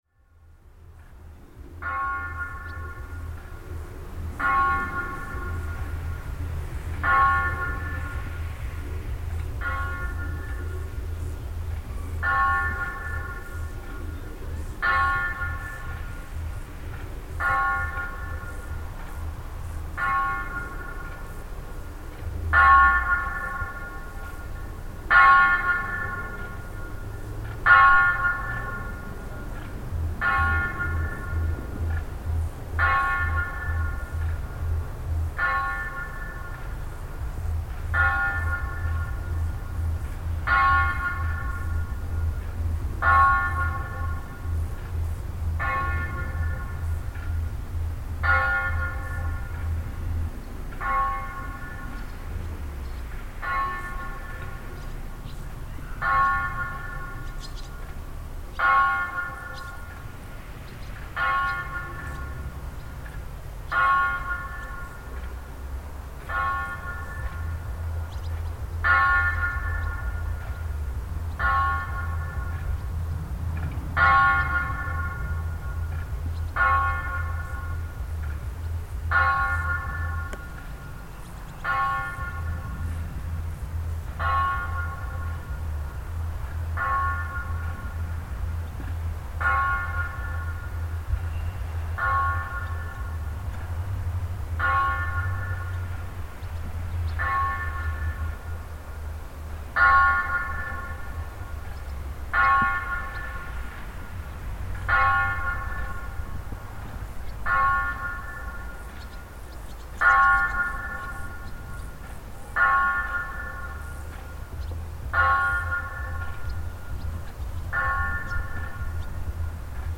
Distant bells from Tomba Brion
Visiting the Carlo Scarpa-designed Tomba Brion, we hear the sound of bells from a nearby town drifting across the fields gently to reach us.
Tomba Brion is a fascinating place: a sloped concrete enclosing wall, two distinct entrances, a small chapel, two covered burial areas, a dense grove of cypresses, a private meditation/viewing pavilion, separated from the main prato by a separate and locked entrance, and a reflecting pool. It's a highly-contemplative space for obvious reasons, which added a new depth to the sound of bells drifting across the sky.